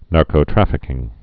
(närkō-trăfĭ-kĭng)